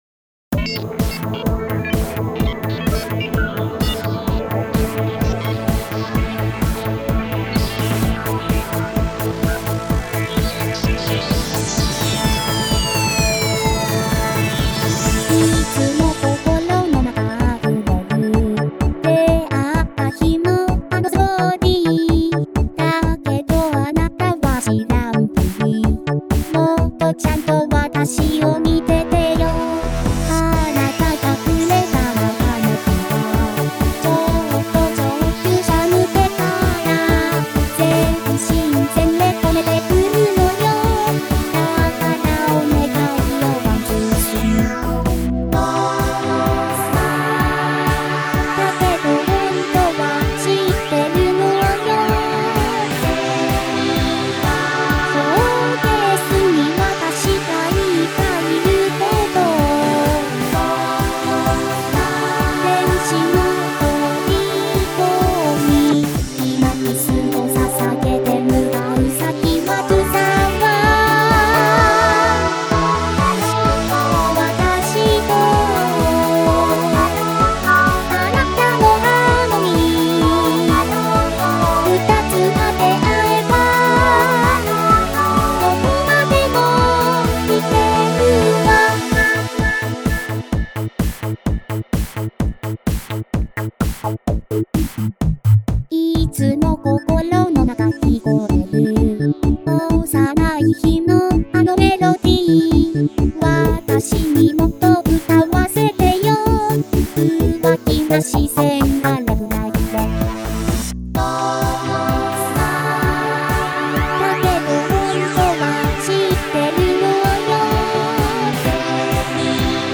自作のテクノポップもどきです。なにぶん初めてのボーカル曲で荒いところが多いです。
VOCALOID